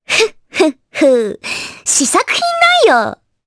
Requina-Vox_Halloween_Skill1_jp.wav